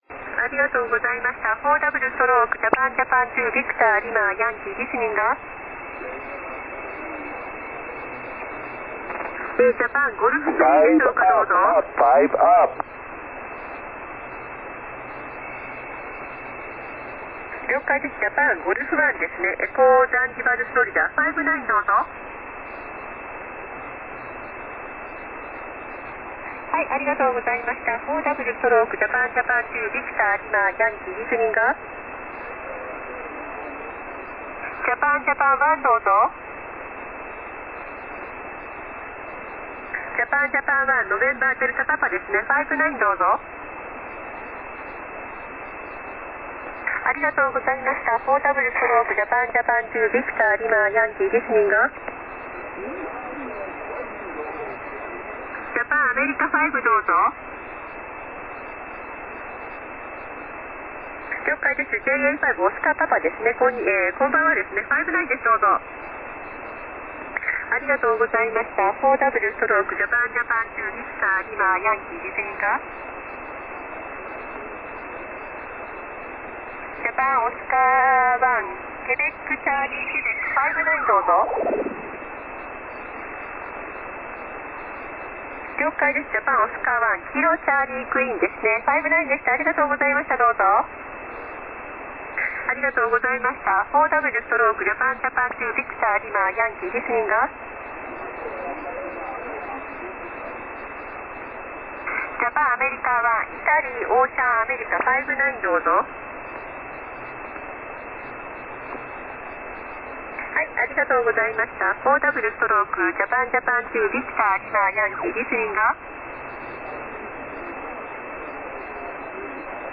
21.202MHz SSB